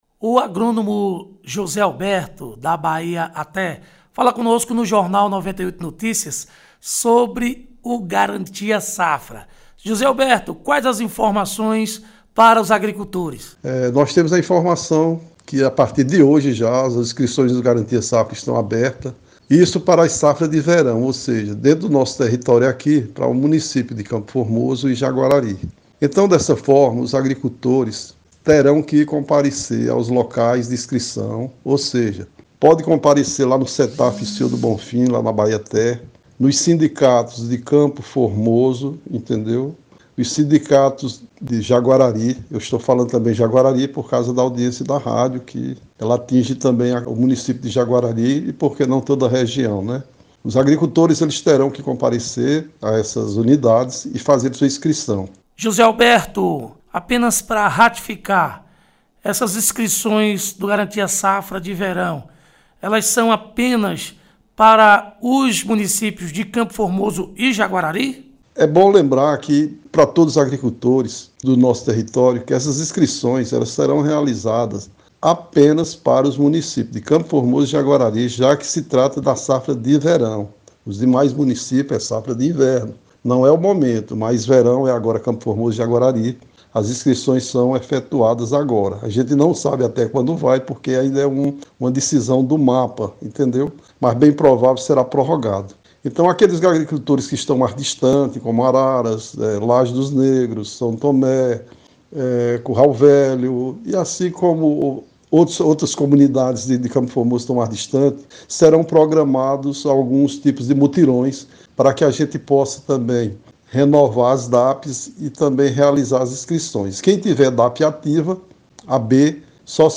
Em entrevista ao 98 Notícias